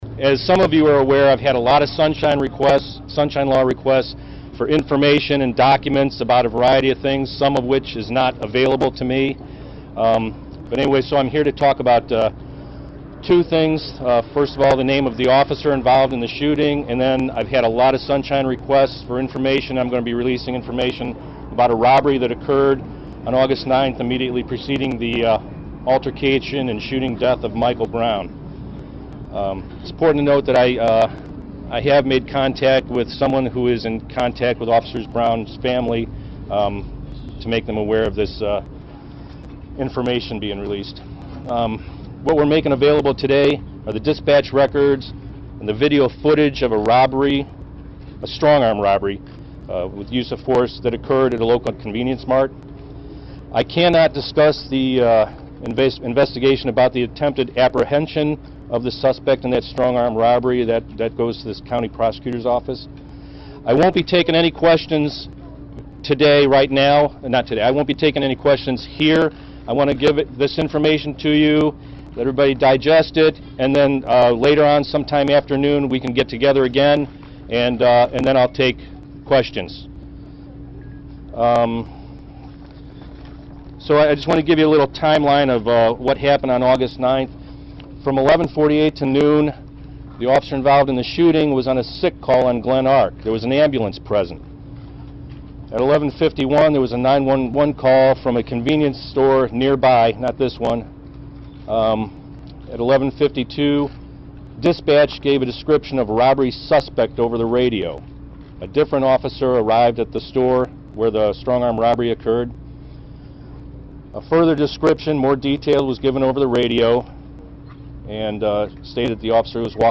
Chief Jackson’s news conference:
AUDIO: Jackson news conference 3:57